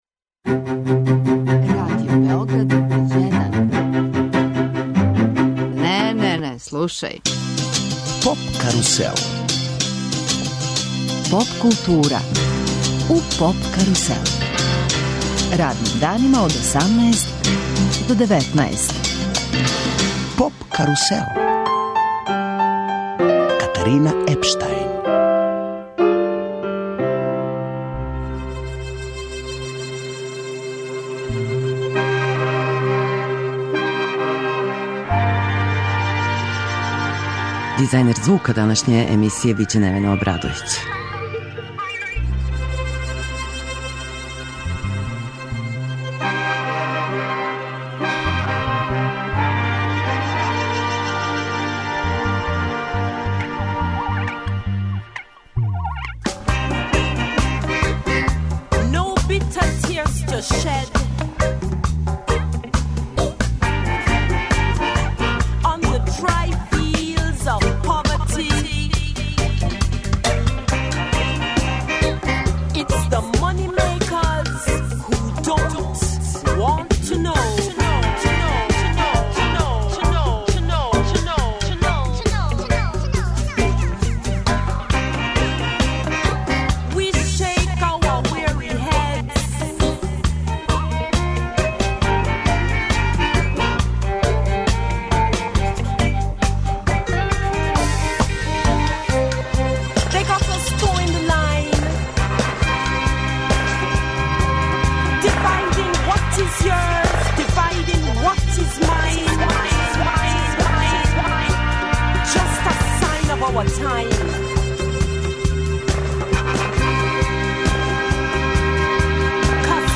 Емитујемо интервју